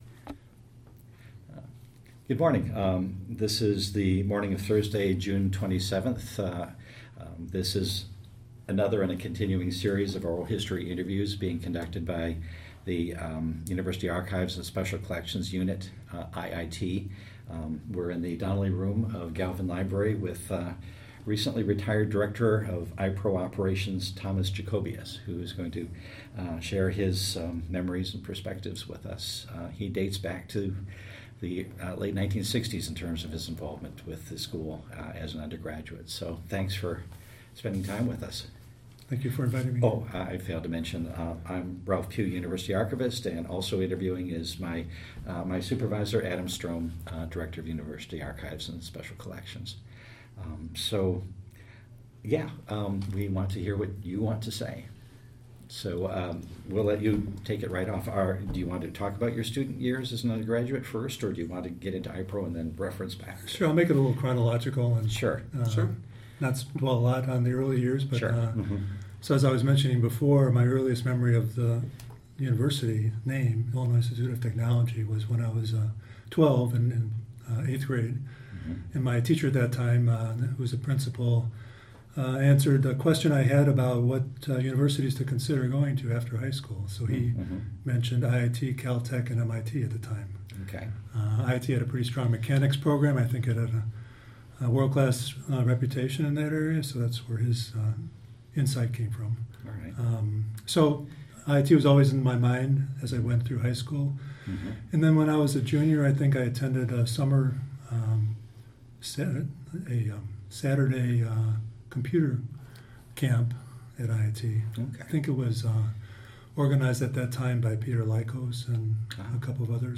Type Interview